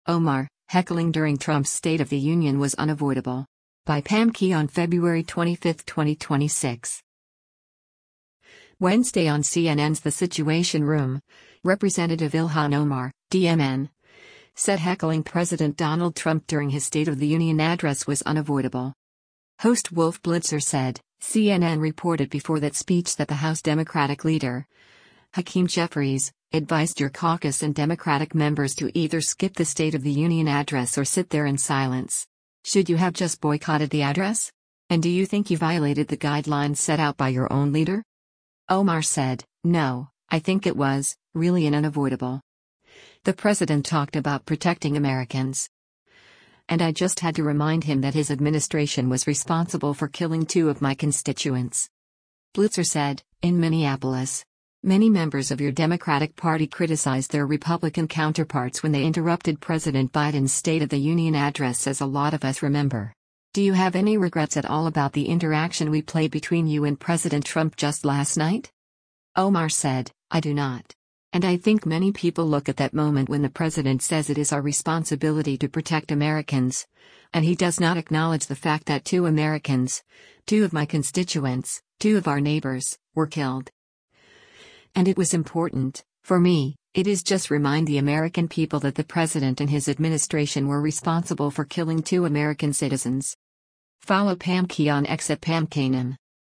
Wednesday on CNN’s “The Situation Room,” Rep. Ilhan Omar (D-MN) said heckling President Donald Trump during his State of the Union address was “unavoidable.”